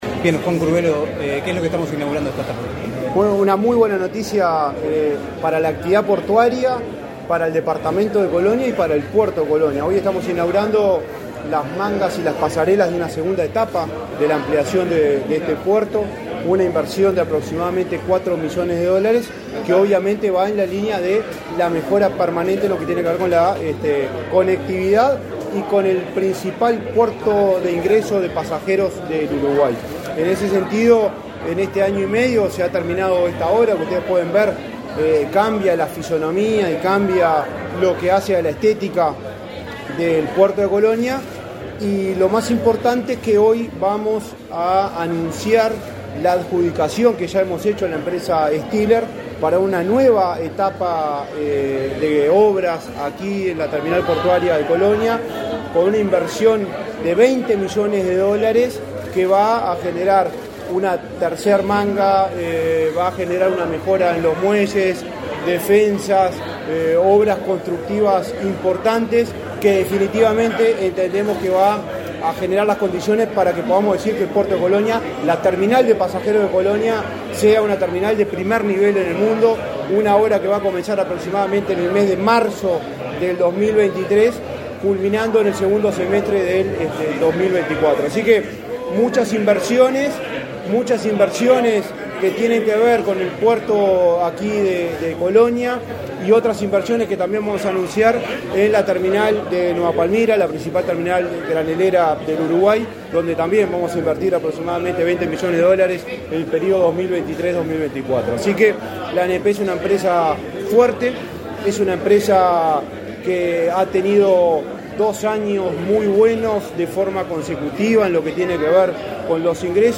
Entrevista al presidente de la ANP, Juan Curbelo
El titular de la Administración Nacional de Puertos (ANP), Juan Curbelo, realizó declaraciones a Comunicación Presidencial.